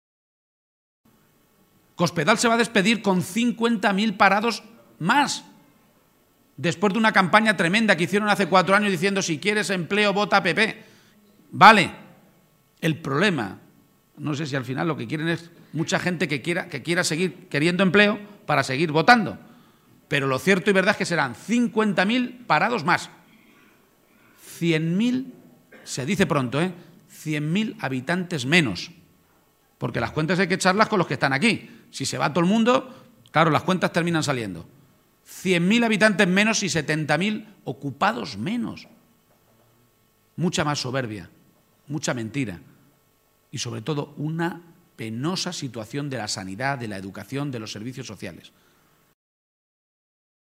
Audio Page en Mondejar-1